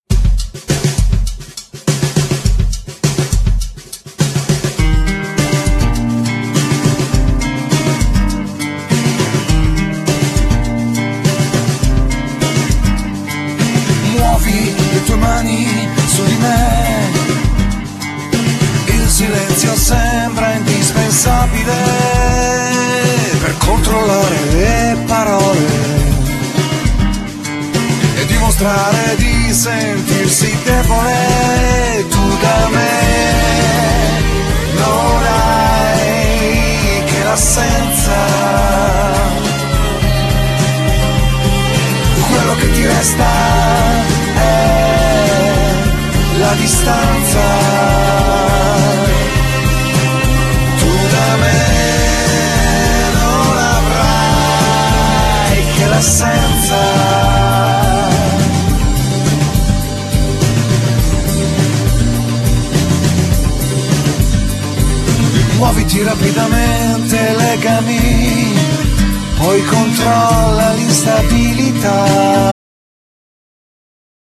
Genere: Pop rock